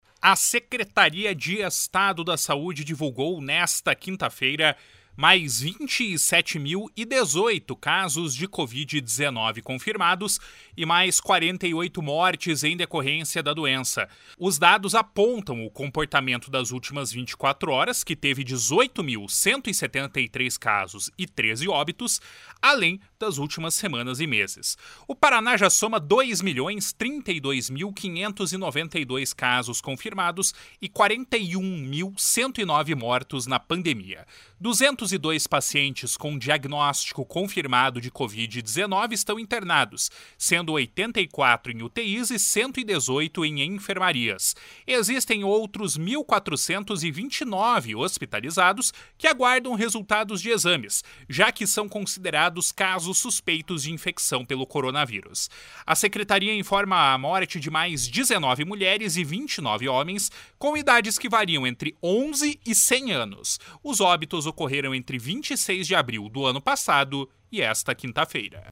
Boletim da Covid-19 confirma mais 27.018 casos e 48 óbitos pela doença